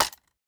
grab-wooden.ogg